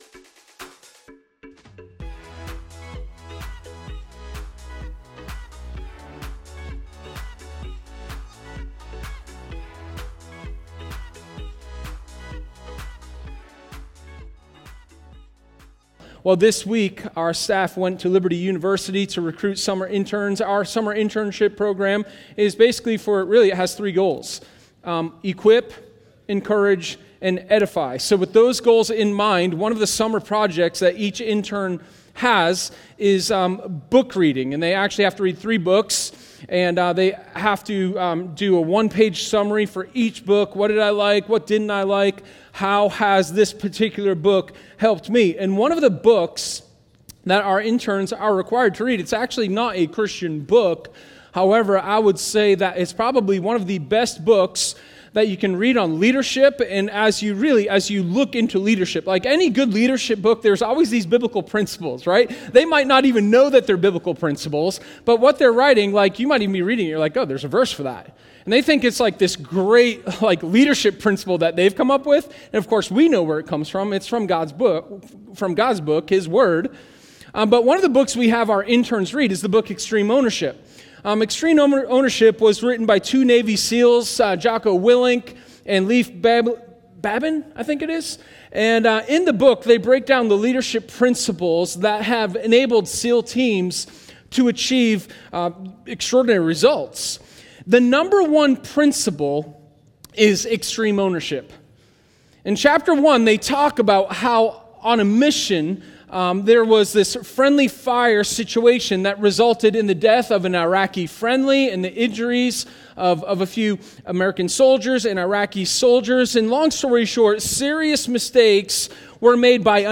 Sermon10_31_Extreme-Ownership.m4a